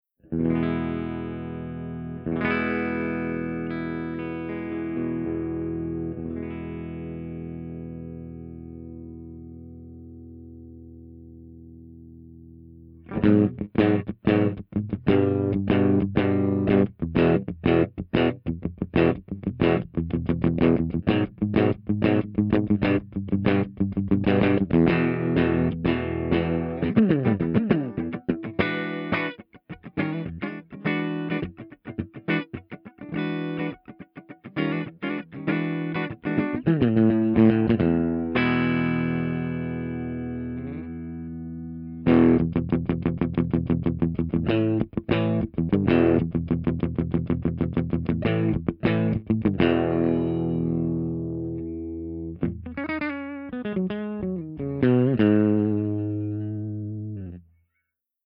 056_FENDERTWIN_WARM_SC.mp3